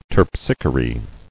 [Terp·sich·o·re]